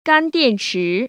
[gāndiànchí] 깐띠앤치